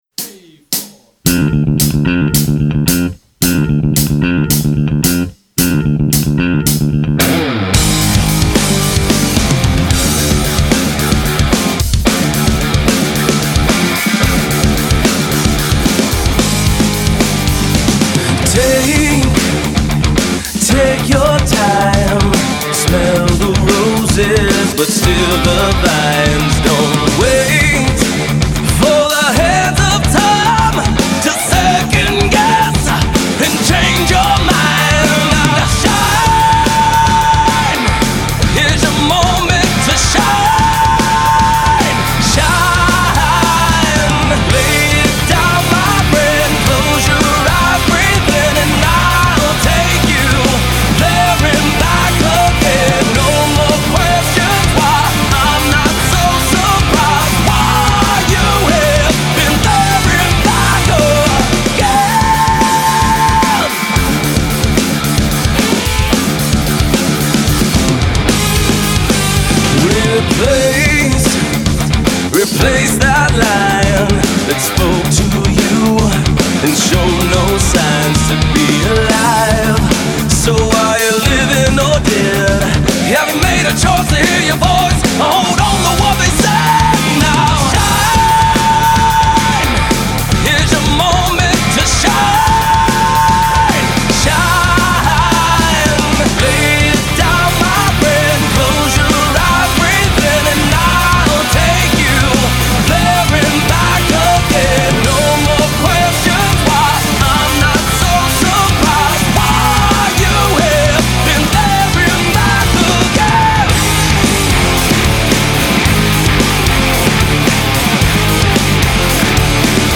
Tags: alternative rock random sounds funny sounds